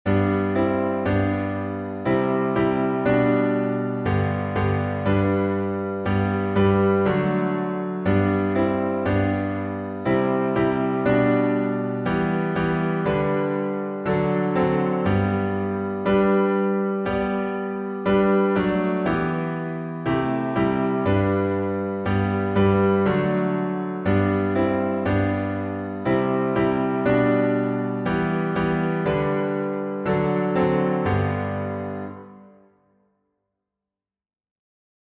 #4001: How Firm a Foundation — Five stanzas in G | Mobile Hymns